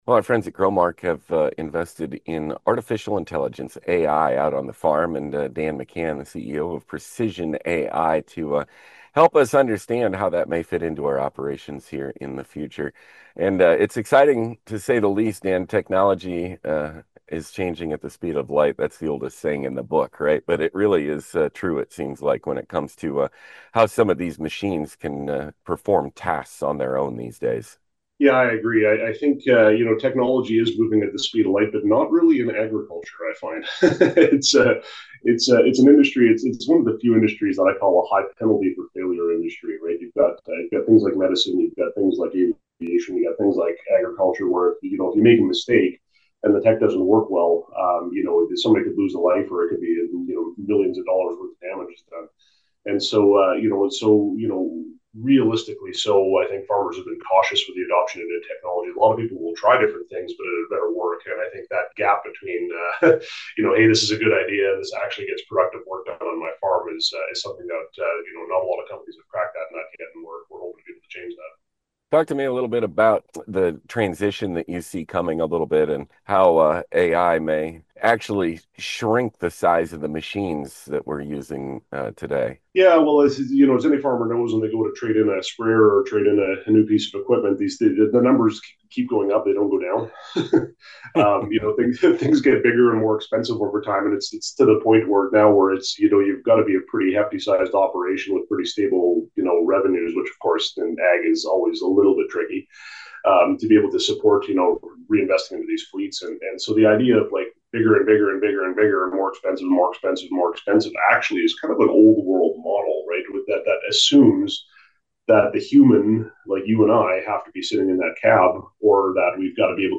FEATURED CONVERSATION